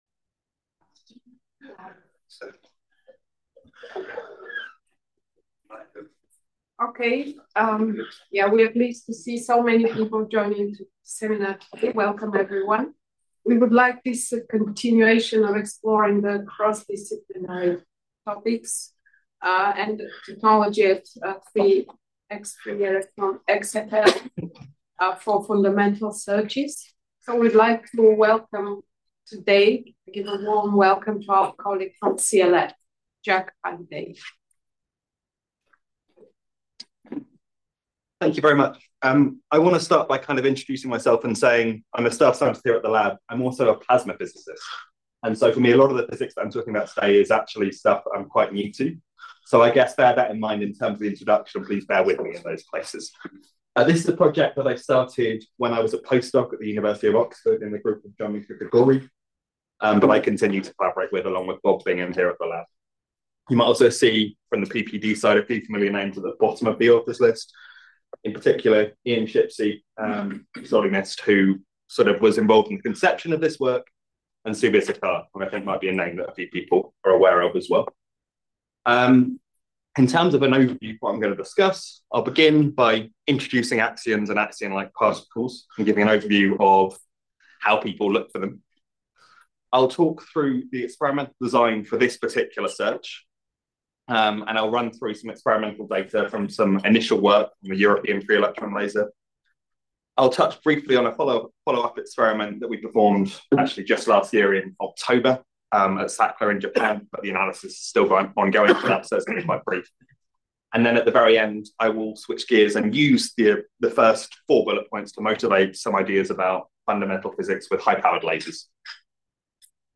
In this seminar I will describe recent direct-detection experiments performed at X-ray Free-Electron Laser (XFEL) facilities, including the European XFEL and follow-up measurements at SACLA. These experiments exploit the Primakoff effect, using the extremely large internal electric fields present in crystalline solids to enable photon-axion-photon conversion in a light-shining-through-a-wall configuration.